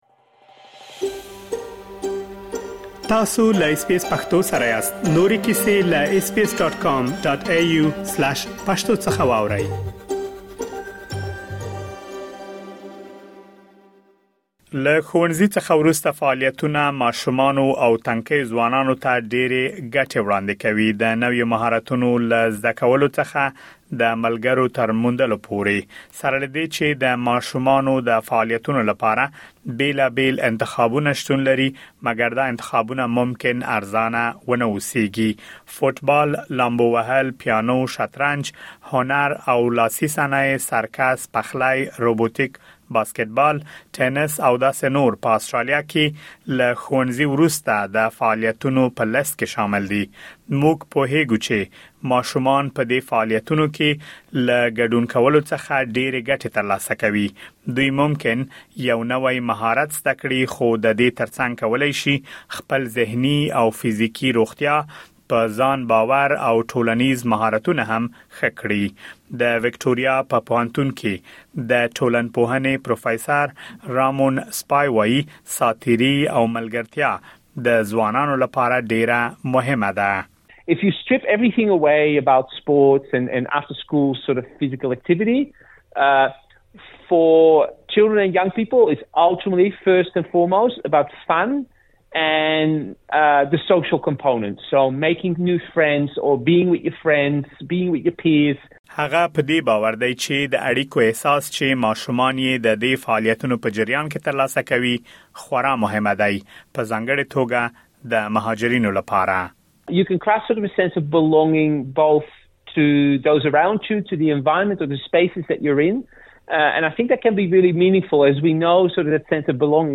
د آسټرالیا پېژندنې په دغه رپوټ کې له ښوونځي څخه وروسته د فعالیت لپاره د ارزانه او ټول شموله ځای موندلو په اړه معلومات را غونډ کړي دي.